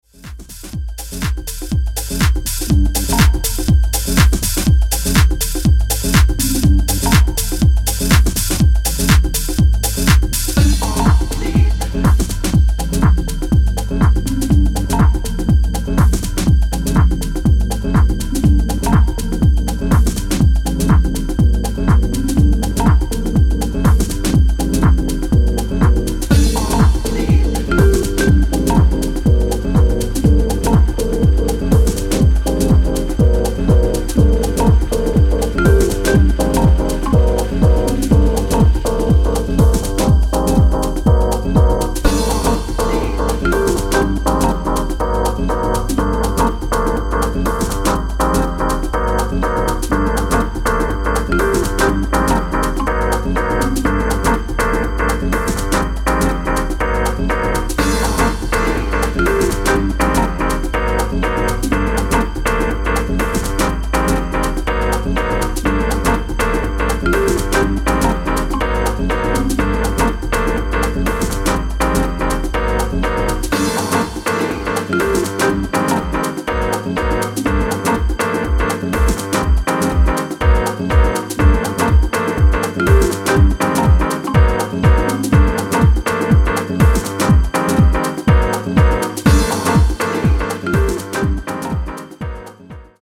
soulful house tracks